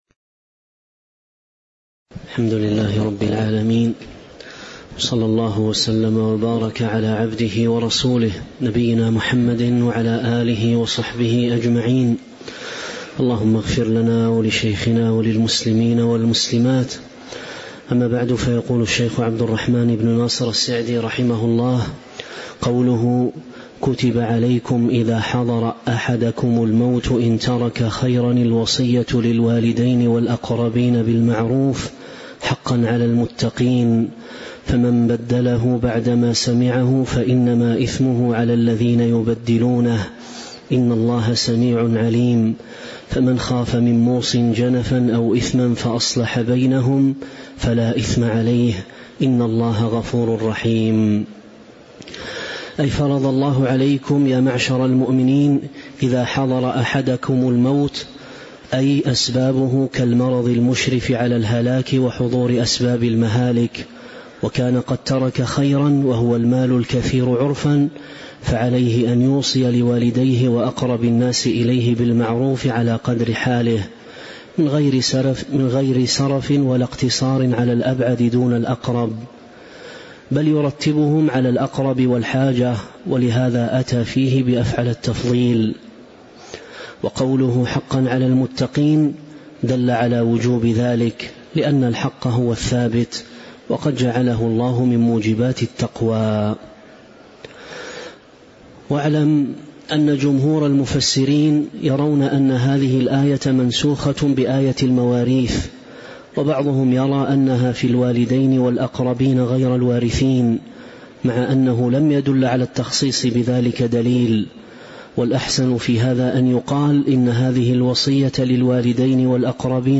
تاريخ النشر ١٣ رجب ١٤٤٦ هـ المكان: المسجد النبوي الشيخ